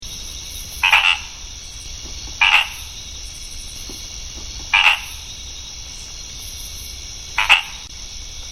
Males call from low vegetation, often far out in the deeper parts of the ponds or aguadas.
Call is series of notes that have been likened to the honking of a goose.